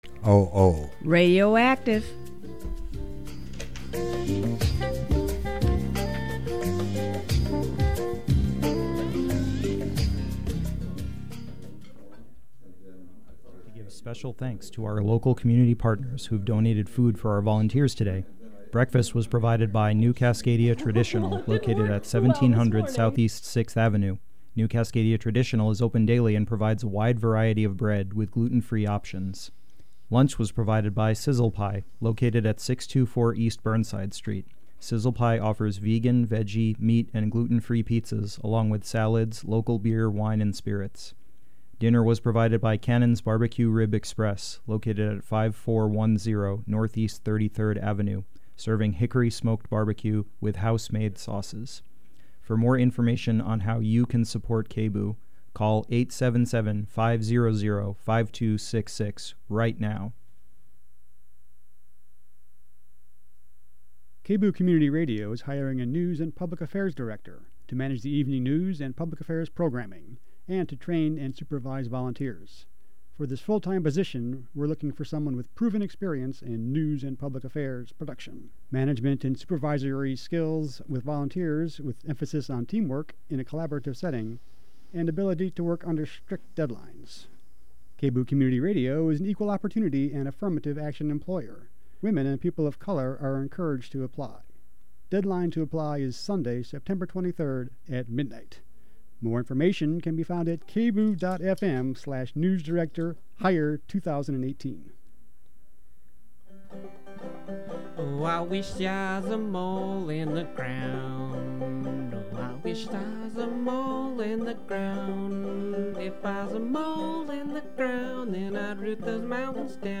In the second of a two part interview